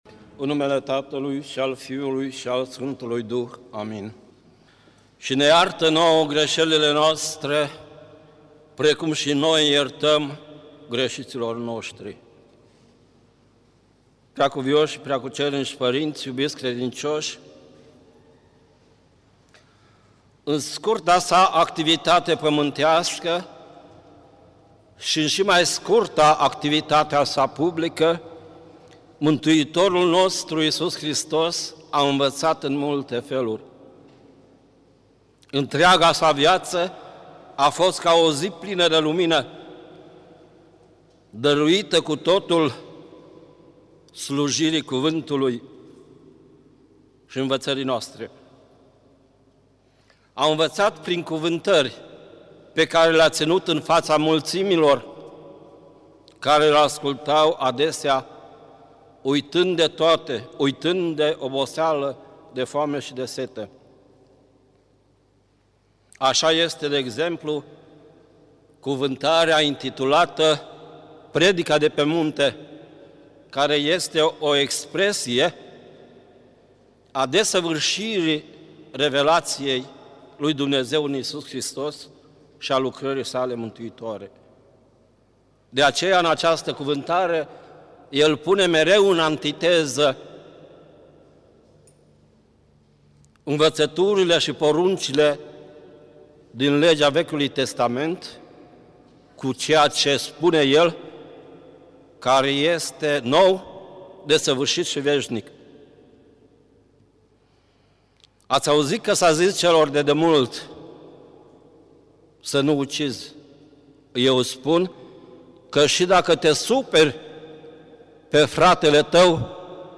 Predică la Duminica a 11-a după Rusalii
Cuvânt de învățătură
rostit în Duminica a 11-a după Rusalii (Pilda datornicului nemilostiv), la Vecernie, la Catedrala